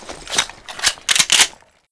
wpn_lightmachgun_reload.wav